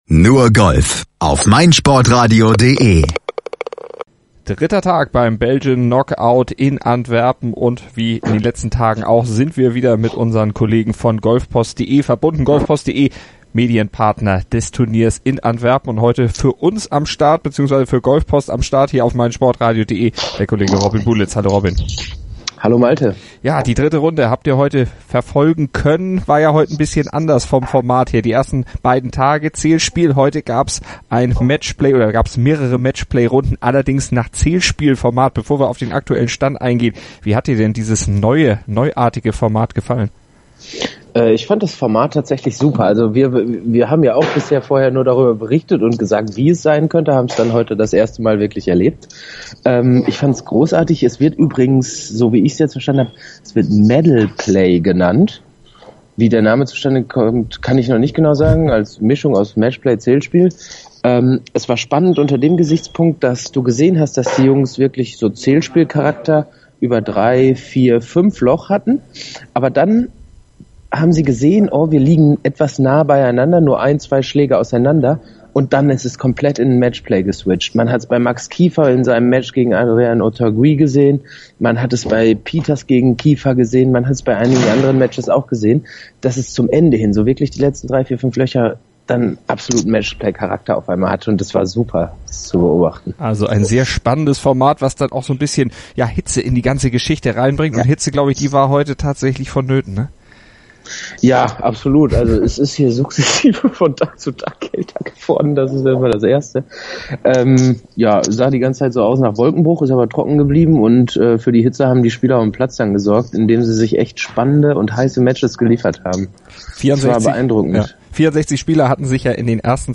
ist vor Ort, hat die Stimmen des Deutschen nach seinem Aus eingefangen